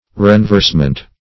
Renversement \Ren*verse"ment\ (-ment), n.